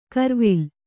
♪ karvil